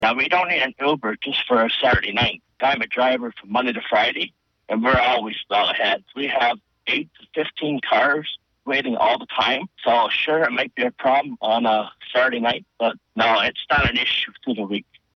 One driver for a local cab company called in and says he doesn’t see the need.